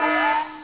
Self-Destruct alarm sound. Works best played back in a loop. (AU format, not as good quality)
alarm.au